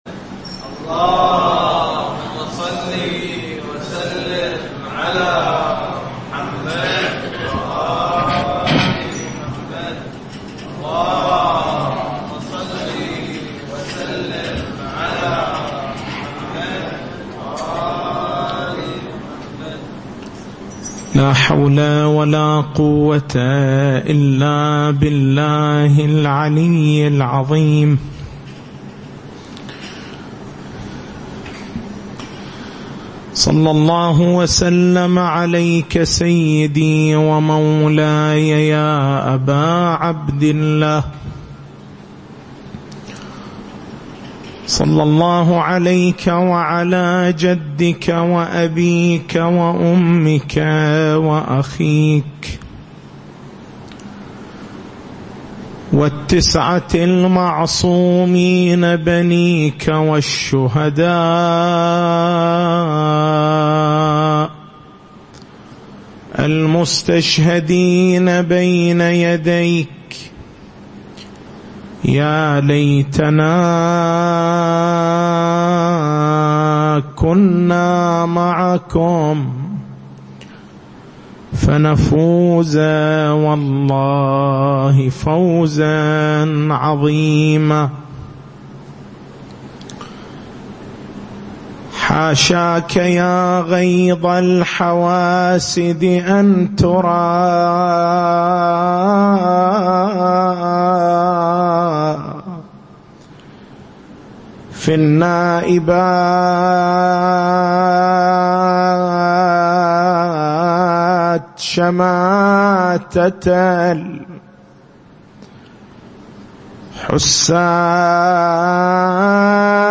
تاريخ المحاضرة: 27/09/1440 محور البحث: كيفية الاستدلال بحديث الاثني عشر خليفة لإثبات ولادة الإمام المهدي (عجل الله تعالى فرجه الشريف).